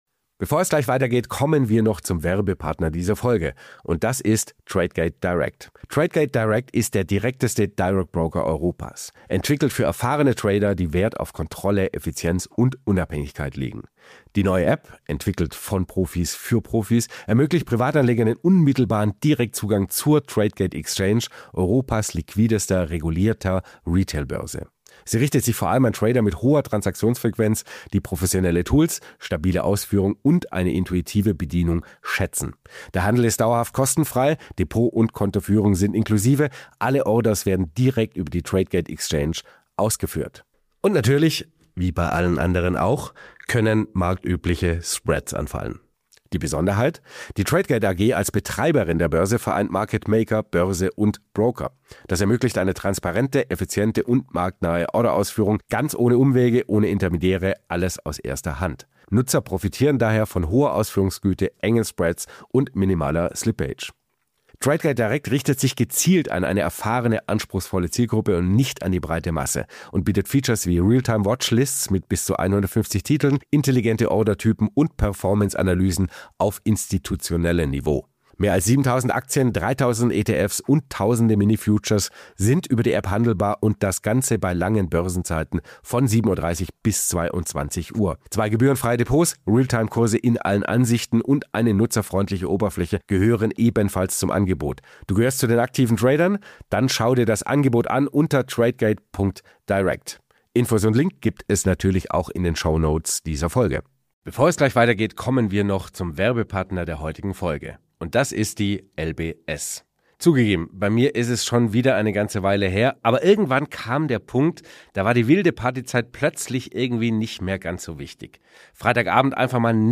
Wir befragen für dich jede Woche die besten Finanz- und Wirtschafts-Experten zu aktuellen Themen rund um dein Geld. powered by FOCUS MONEY